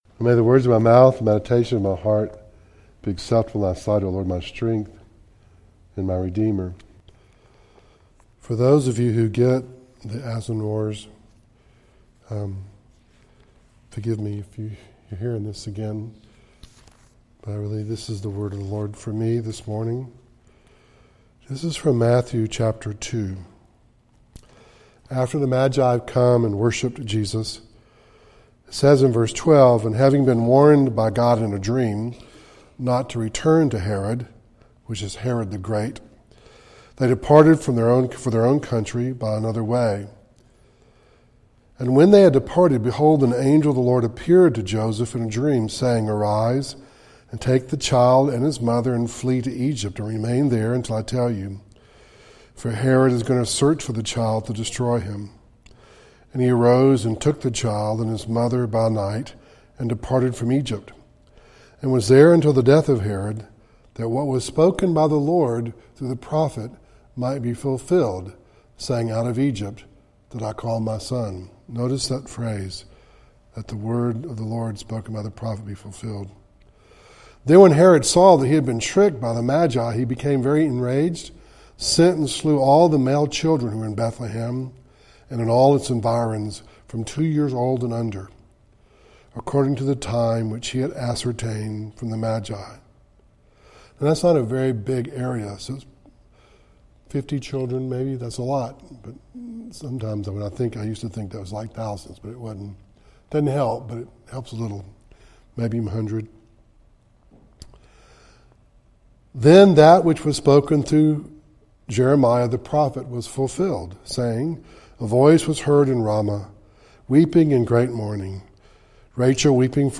Series: Audio Devotionals
Service Type: Devotional